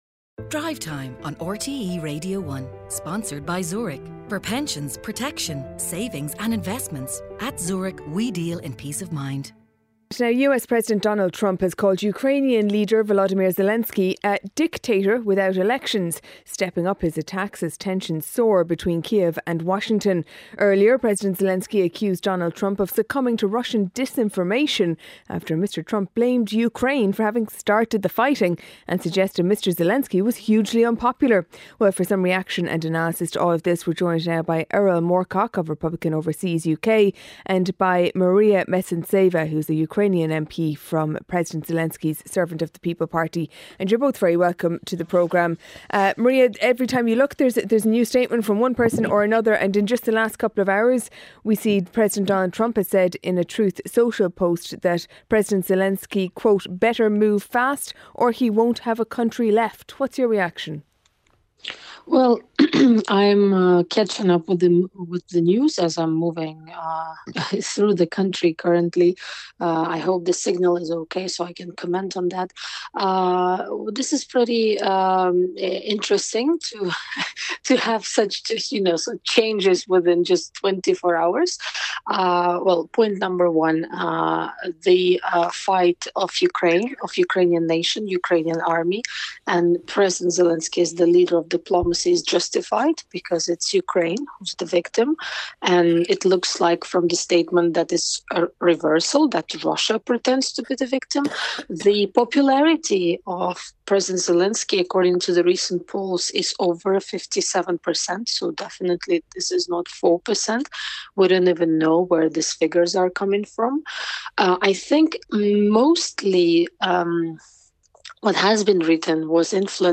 Featuring all the latest stories, interviews and special reports.